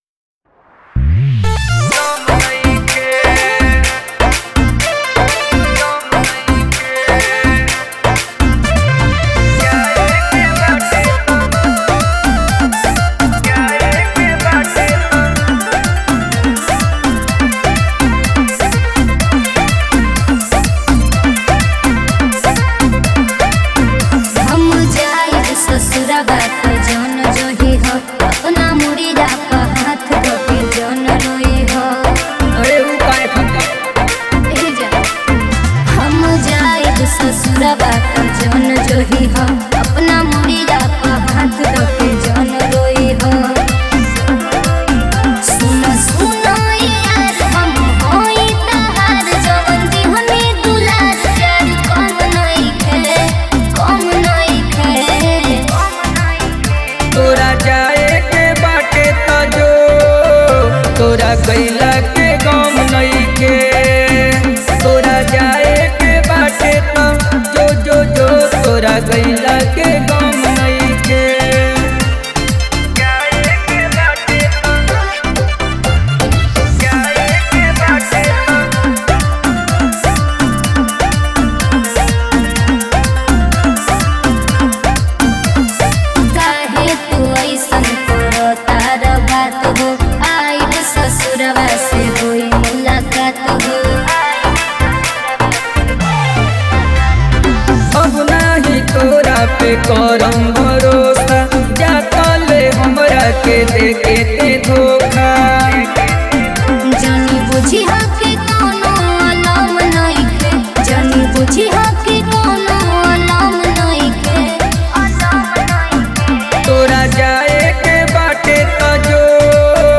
125 BPM